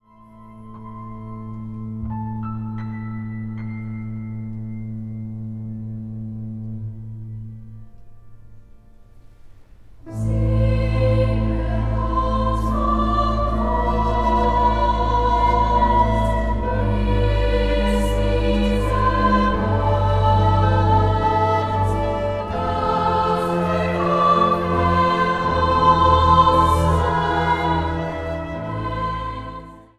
vleugel
orgel
hobo
fluiten
hoorn | Strijkkwintet
spreekstem.
Zang | Gemengd koor
Zang | Meisjeskoor